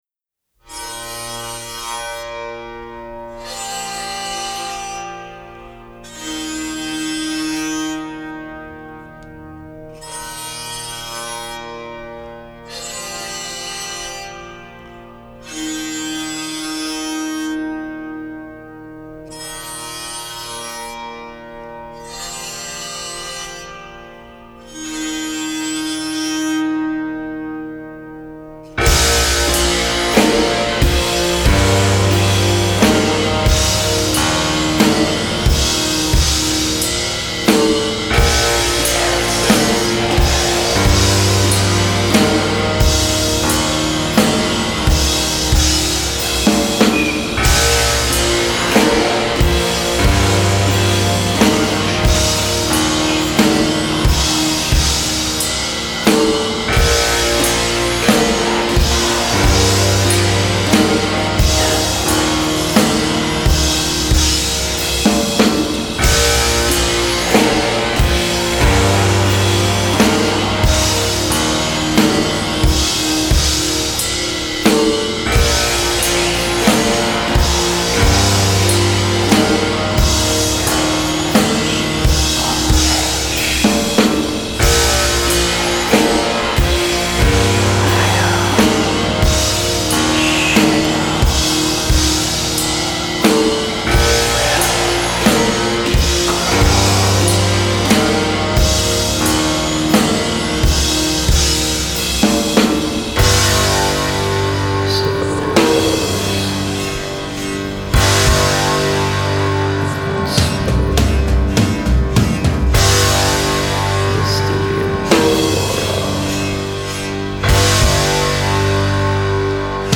The songs are longer, more plodding, more musical.